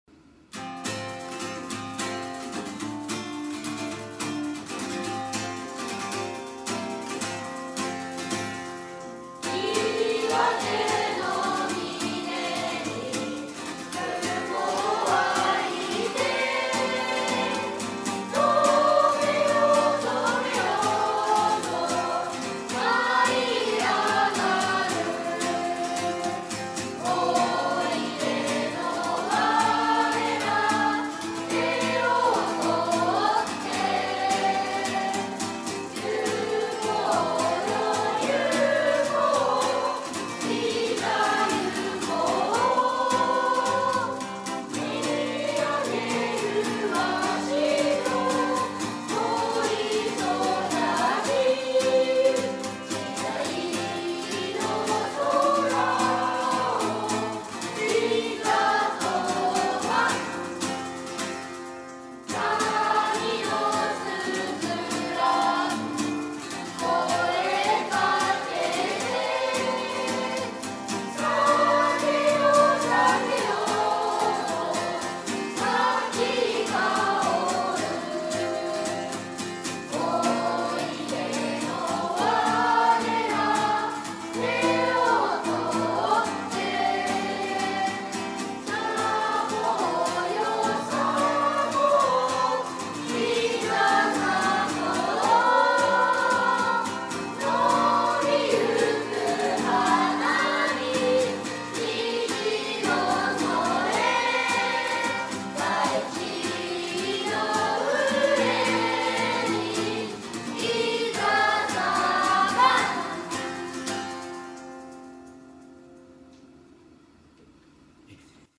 校歌（29日前日練習録音）